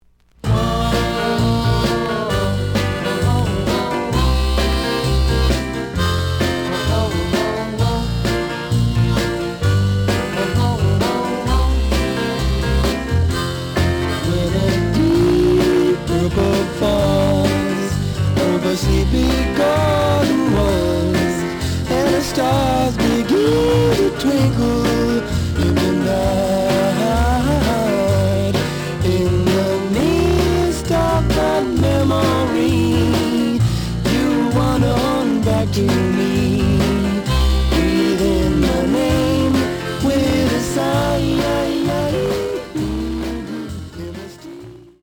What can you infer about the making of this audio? The audio sample is recorded from the actual item. A side is slight cracking sound.